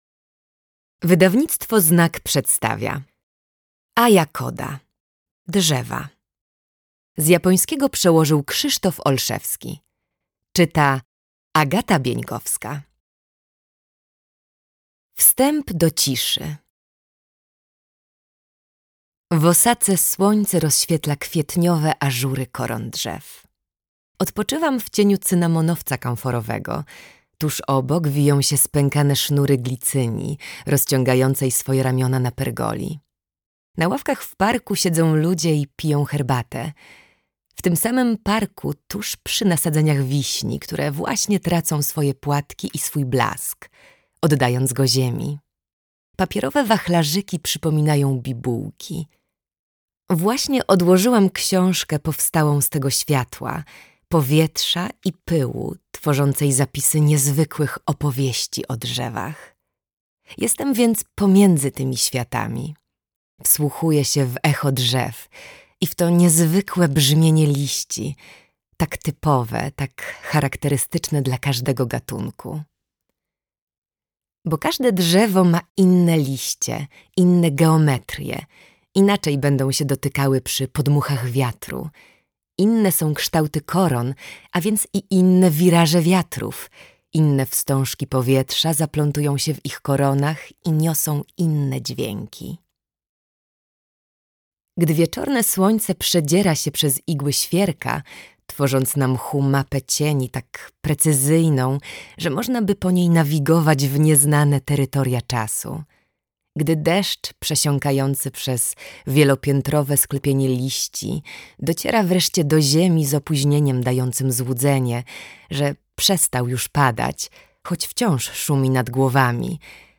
Drzewa - Kōda Aya - audiobook